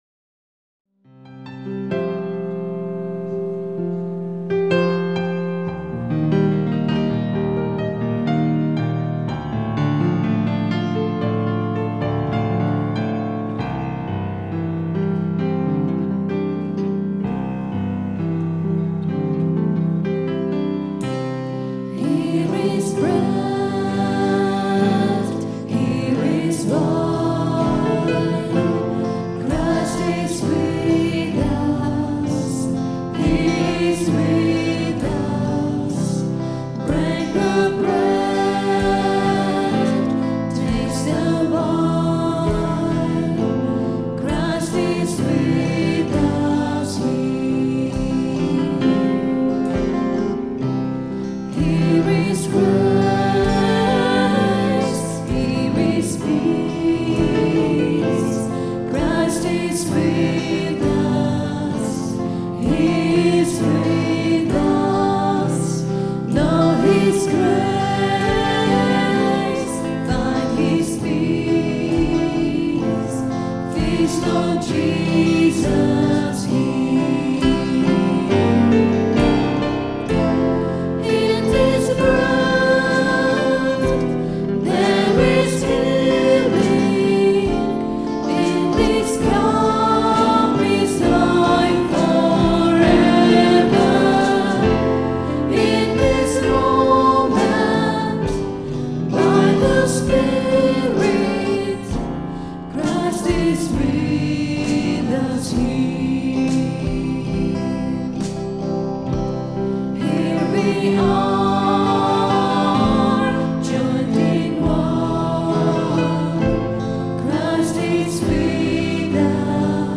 A nice, gentle song ideal for Communion. This song was recorded at 10am Mass on Sunday 27th July 2008. Recorded on the Zoom H4 digital stereo recorder through a Behringer SL2442FX mixer.